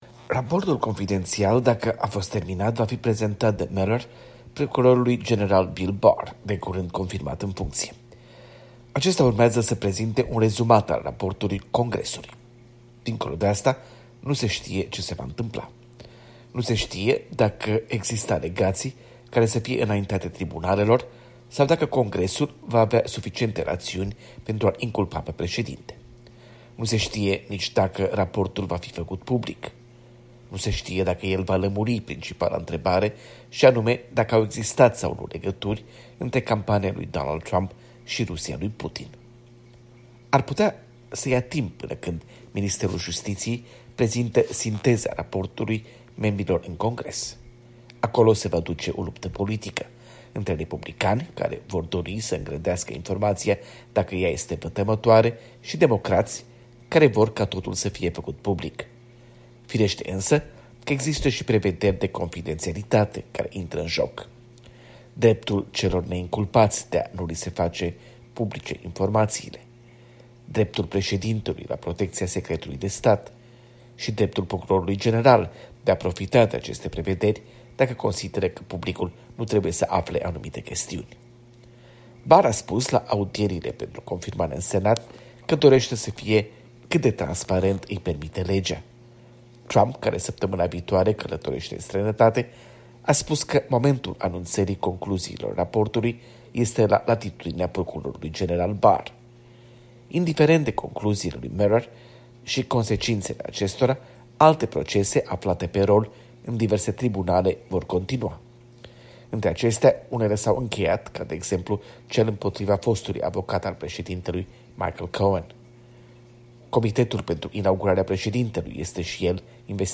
O relatare de la corespondentul Europei Libere la Washington.